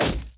Spell Impact 1.wav